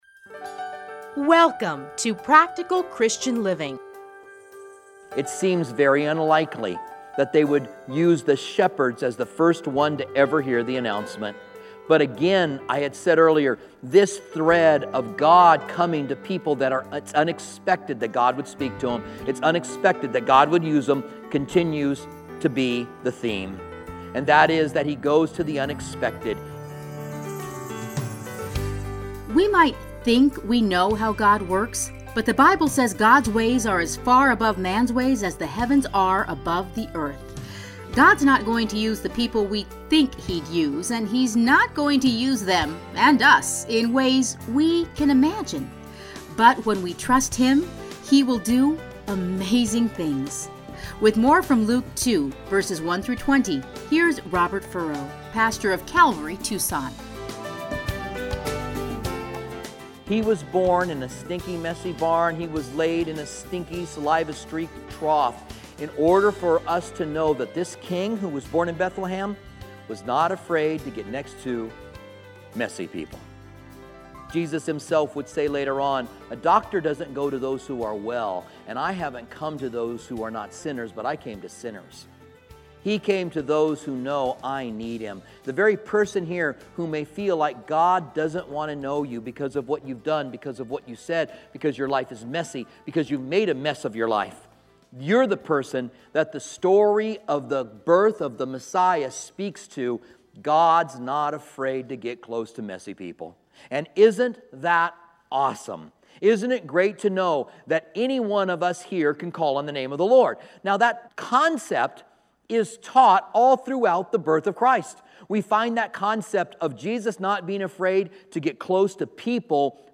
Listen here to a special Christmas message.